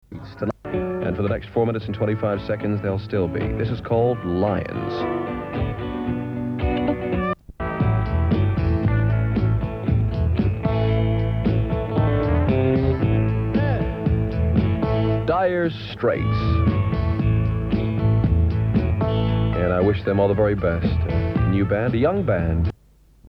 You are right, that is the interview I was talking about, and in fact the Lions snippet IS NOT part of that interview, my mistake (it was immediately BEFORE the interview on the Leeds tape, it is a different speaker voice as well!)